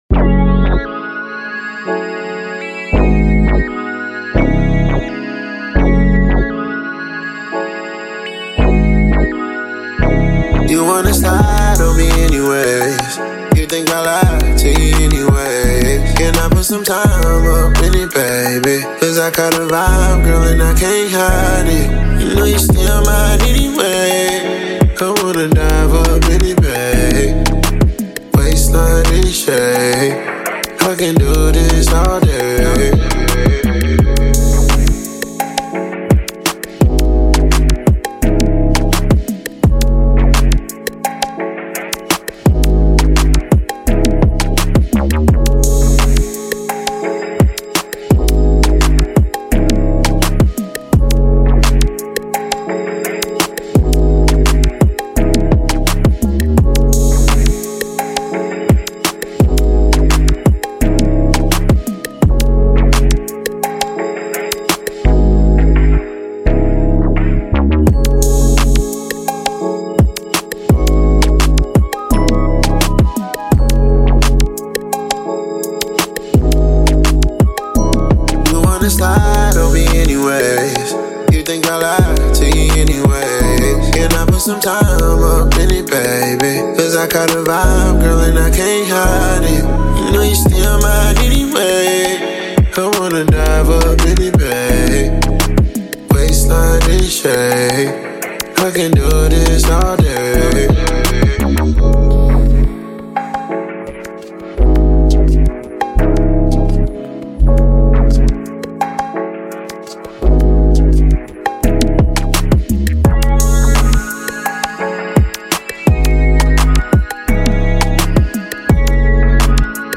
Instrumental With Hook